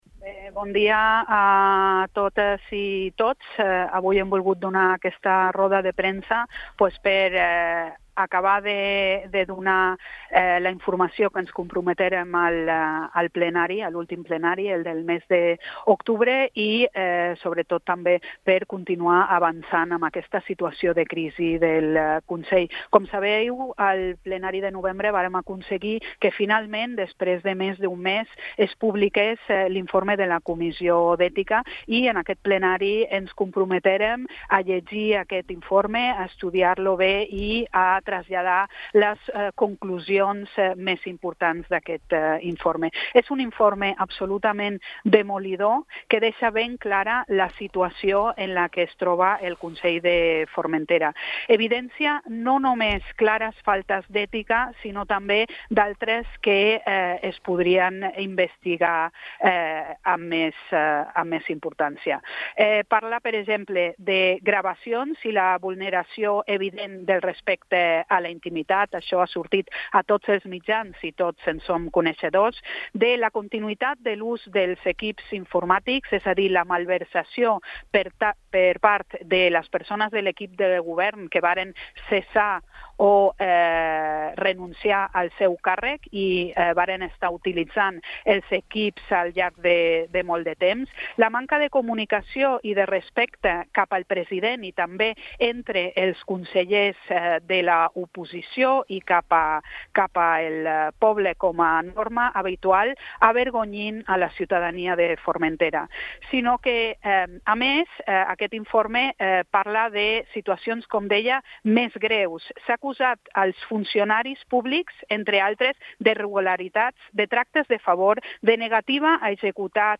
Aquestes declaracions han tengut lloc en una roda de premsa convocada aquest matí davant de la seu del Consell.